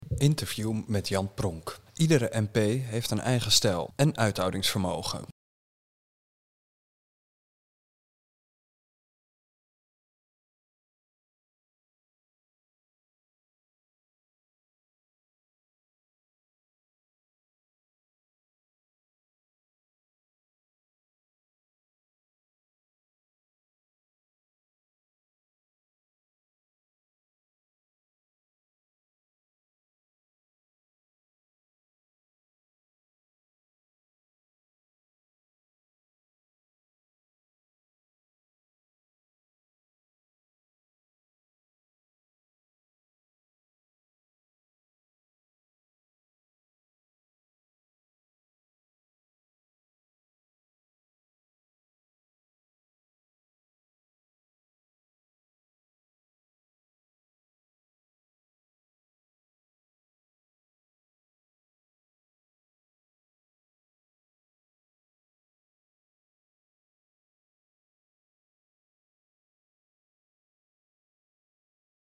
Interview met Jan Pronk